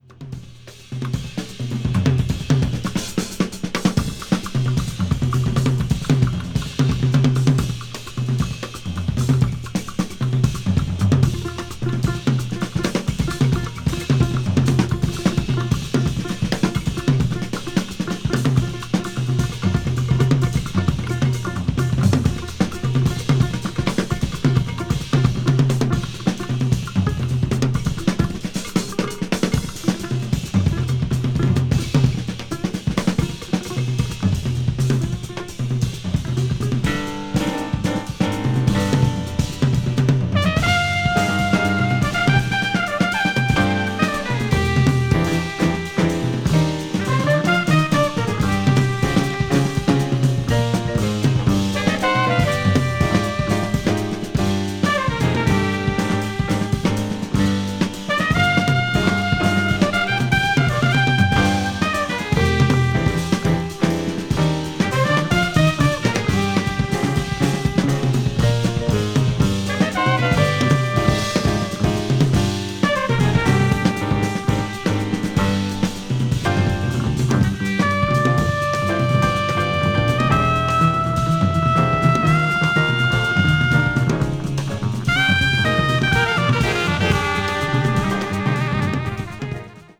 contemporary jazz   modal jazz   post bop   spiritual jazz